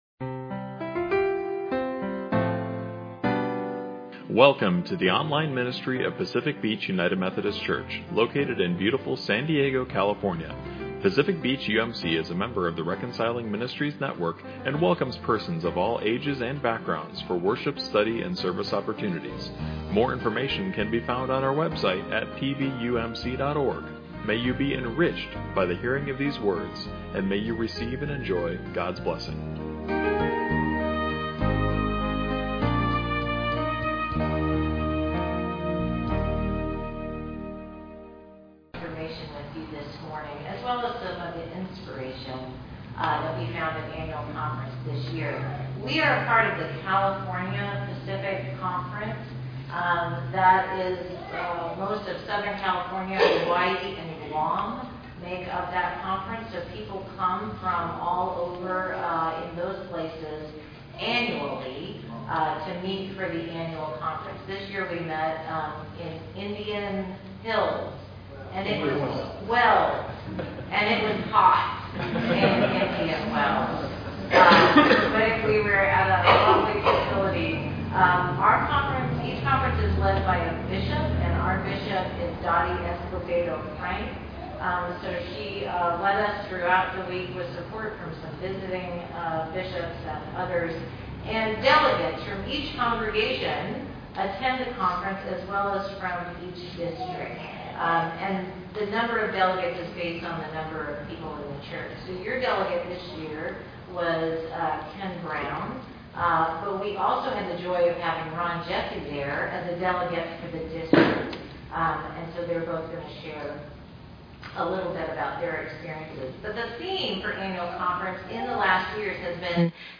Today they share updates on the Conference and reflections on this year’s theme, “Cherish.”